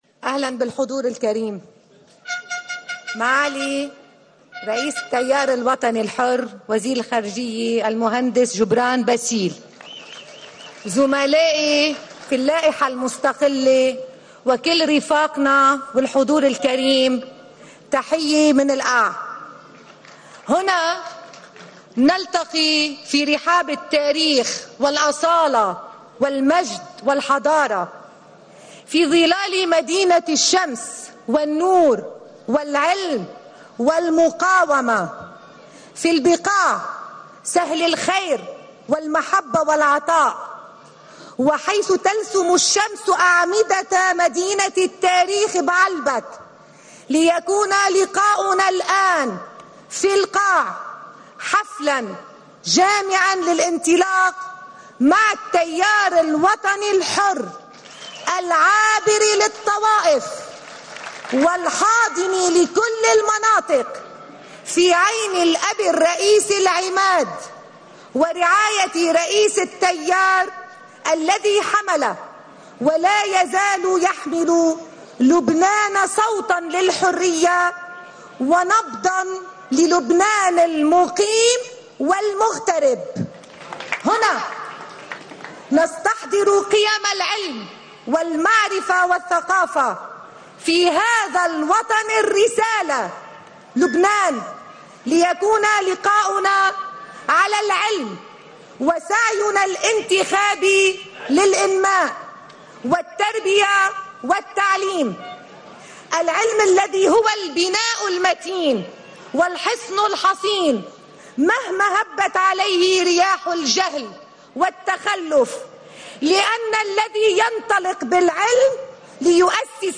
كلمة